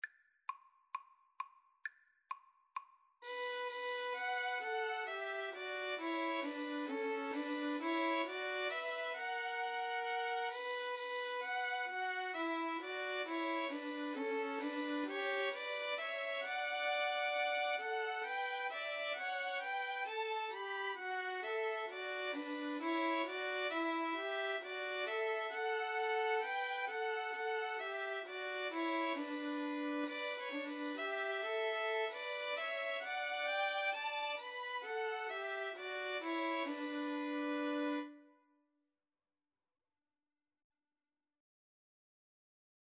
Violin 1Violin 2Viola
The melody is in the minor mode.
4/4 (View more 4/4 Music)